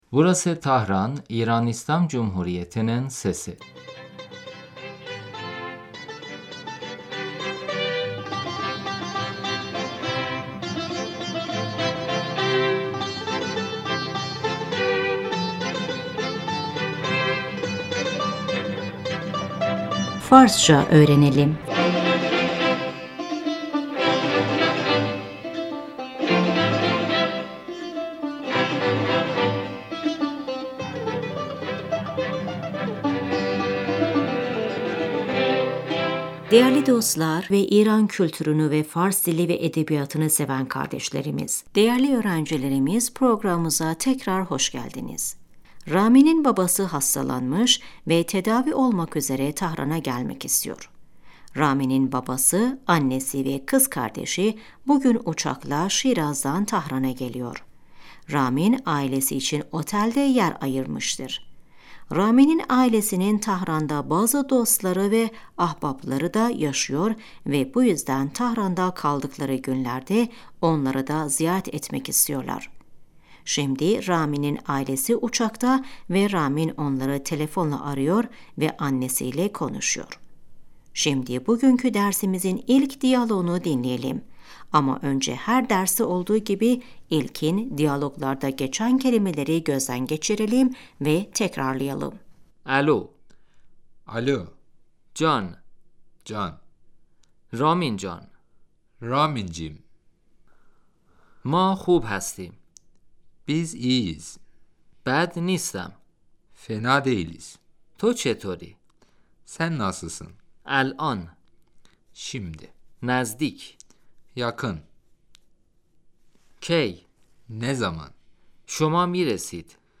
صدای مکالمه تلفنی Telefonla görüşme sesi رامین - الو ؟